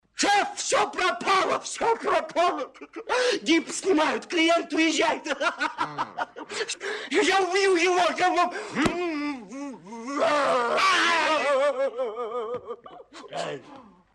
Звуки цитат из фильма
В подборке — короткие, но узнаваемые фразы из популярных кинокартин, подходящие для установки на звонок, использования в мемах или видеороликах.